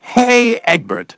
synthetic-wakewords / hey_eggbert /ovos-tts-plugin-deepponies_Discord_en.wav
ovos-tts-plugin-deepponies_Discord_en.wav